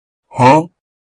Huh